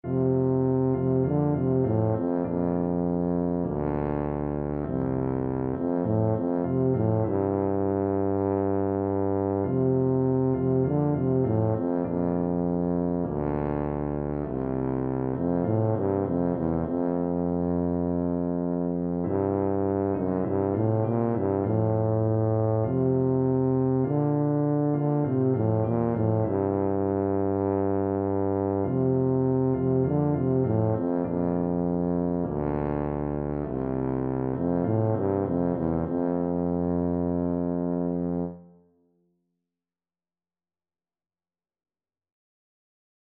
Christian
F major (Sounding Pitch) (View more F major Music for Tuba )
4/4 (View more 4/4 Music)
Classical (View more Classical Tuba Music)